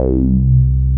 RUBBER C3 P.wav